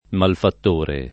malfatt1re] s. m.; f. (raro) ‑trice (pop.